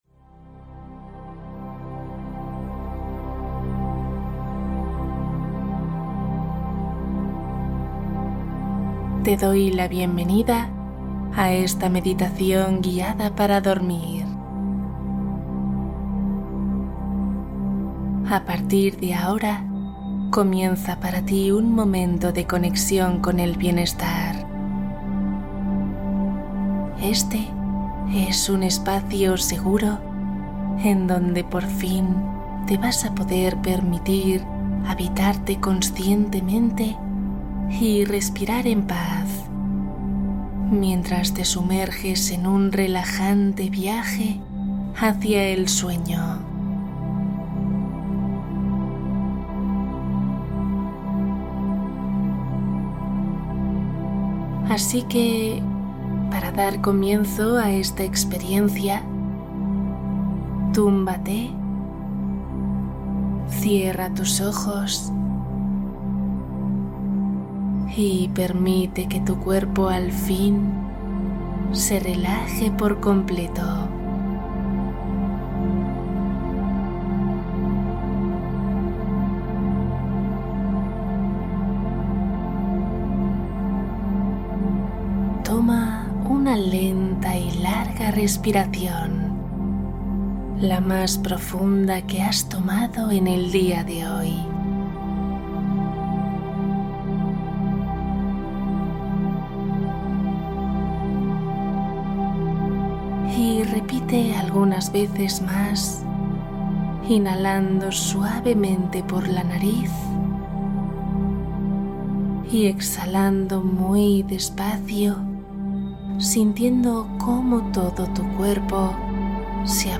Meditación para dormir profundamente Viaje hacia la paz interior